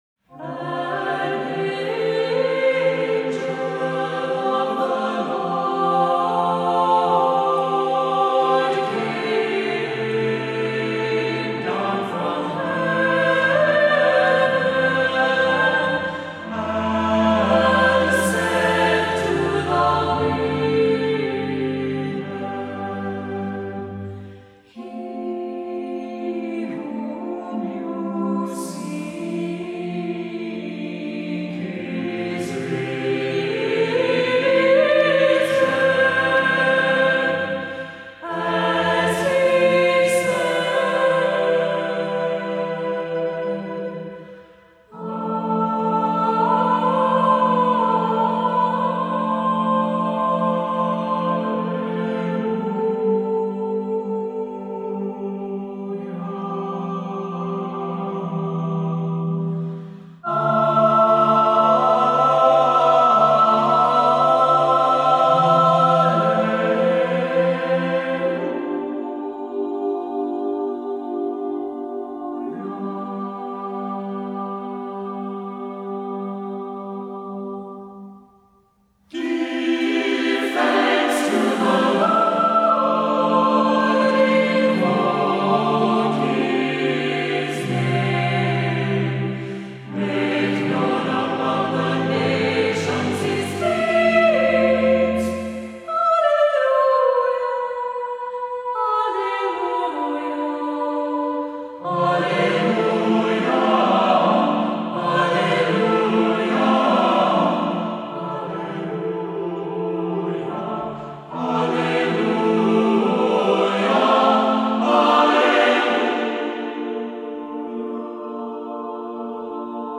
Voicing: SATB,a cappella